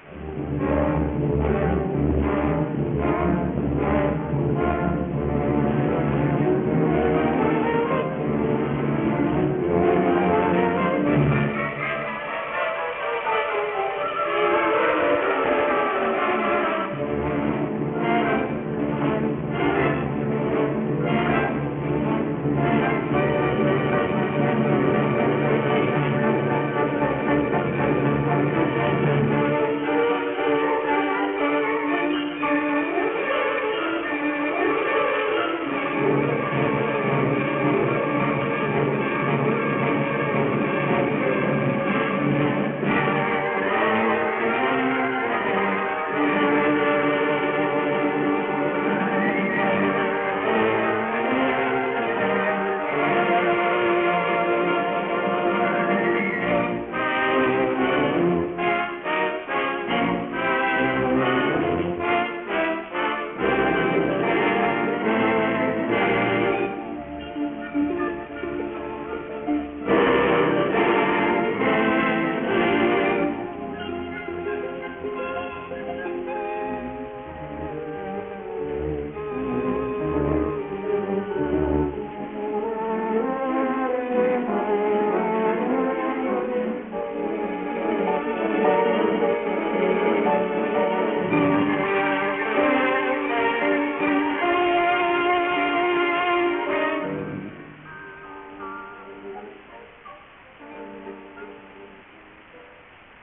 Musica
Track Music